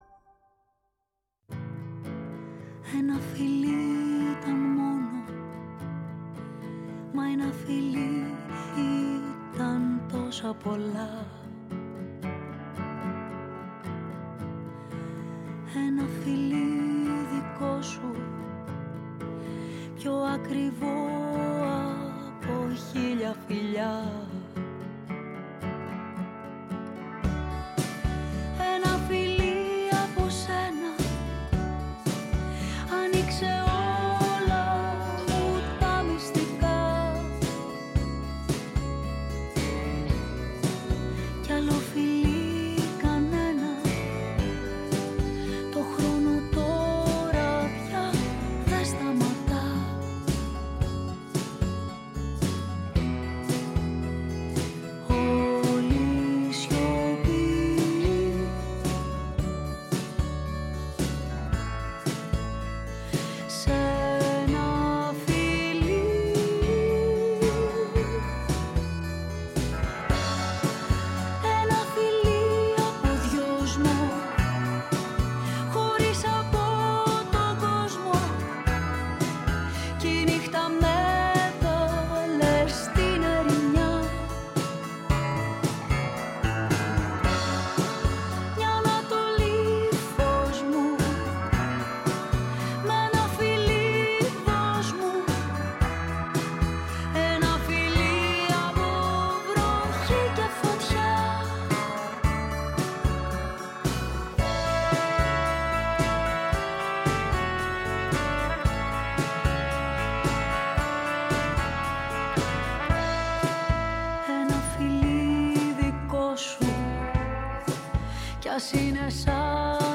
Στο στούντιο της “Φωνής της Ελλάδας”